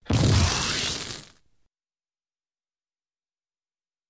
Звуки исчезновения
От таинственных магических эффектов до забавных фантастических переходов — здесь есть всё для создания атмосферы.